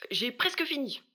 VO_ALL_Interjection_07.ogg